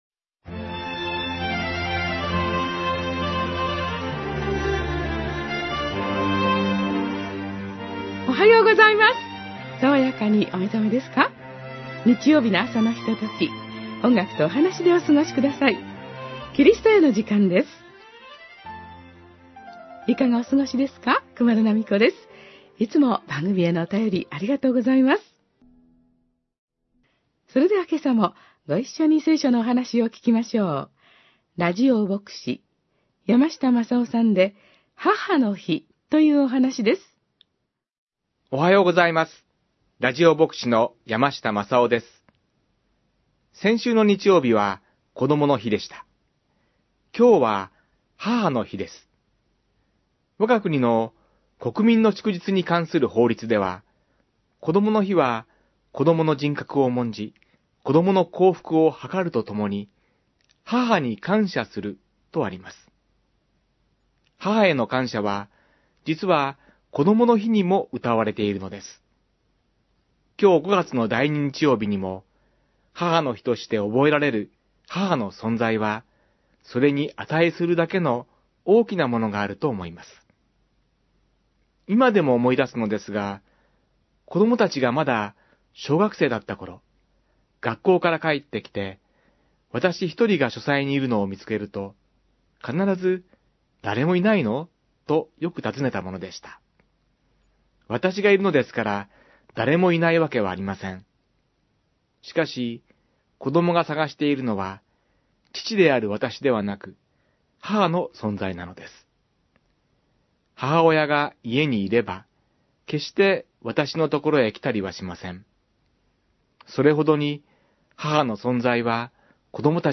メッセージ： 母の日